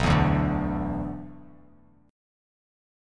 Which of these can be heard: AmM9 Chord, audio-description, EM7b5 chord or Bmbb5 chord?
Bmbb5 chord